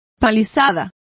Complete with pronunciation of the translation of palisade.